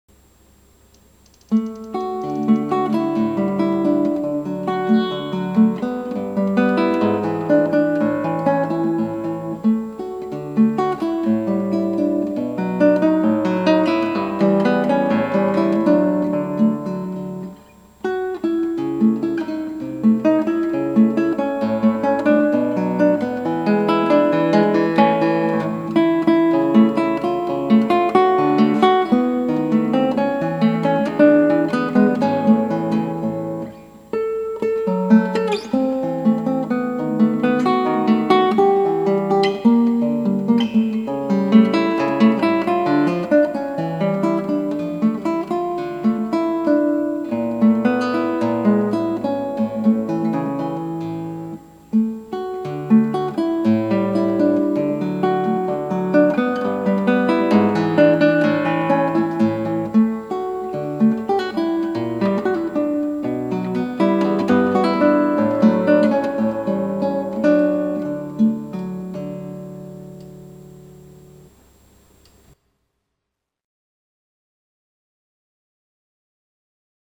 クラシックギター　ストリーミング　コンサート